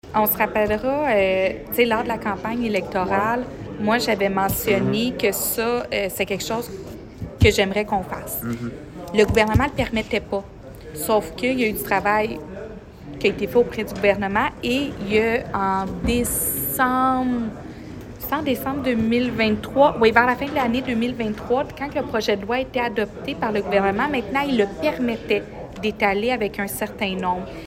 La mairesse de Granby, Julie Bourdon.